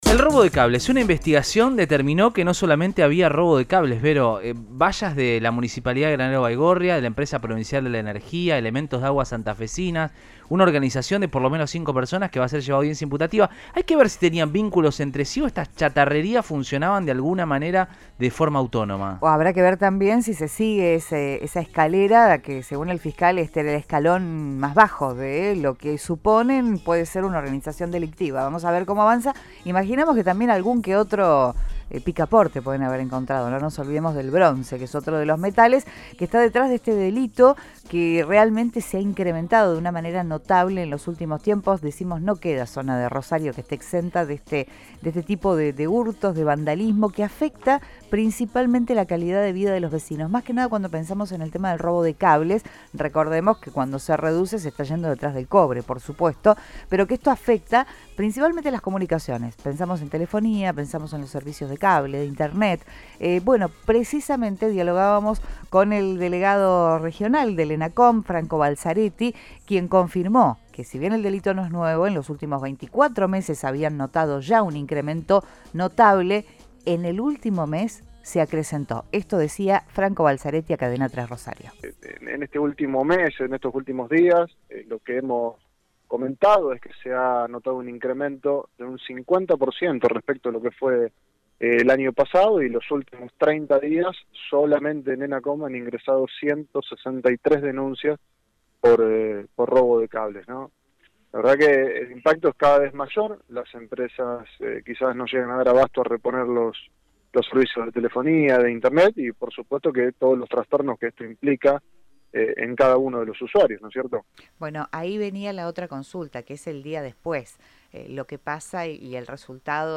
Delegado Regional del ENACOM, Franco Balzaretti confirmó a Cadena 3 Rosario que si bien el delito no es nuevo en los últimos meses notaron un incremento notable.